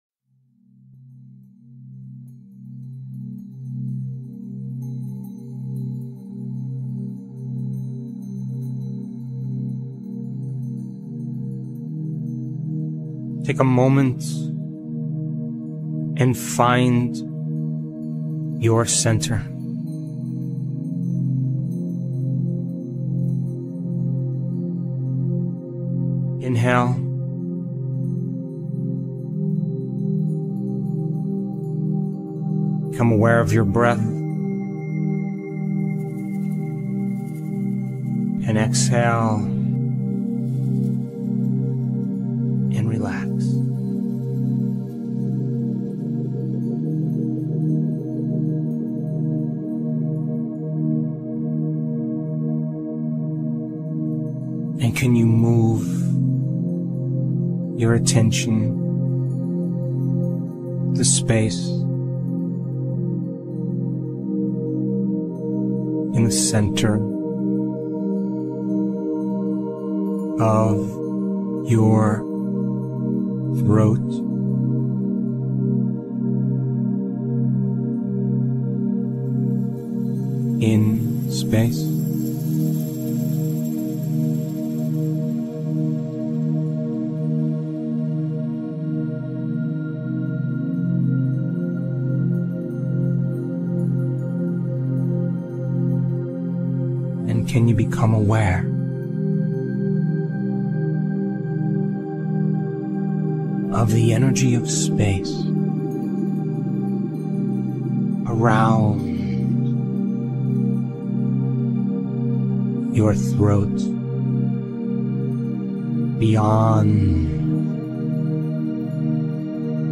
If you prefer a guided meditation, click HERE (and then the 3 vertical dots) to download an excellent 16-minute guided meditation by Dr. Joe Dispenza.
dr-joe-meditation